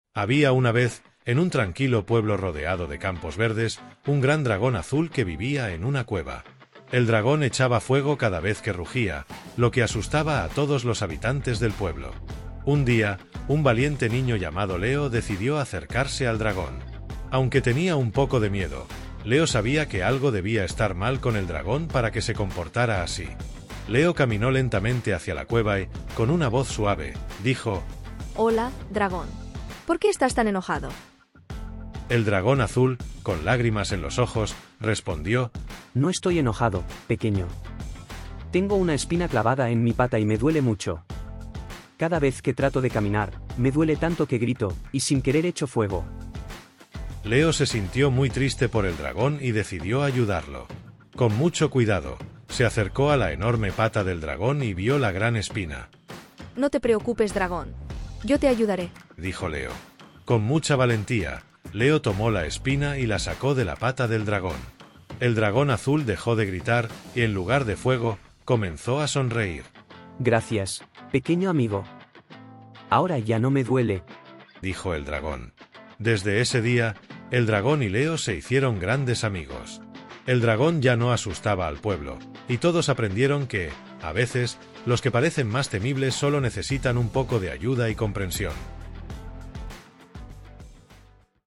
1.-Audiolibro-El-dragon-azul-y-el-pequeno-leo.mp3